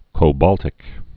(kō-bôltĭk)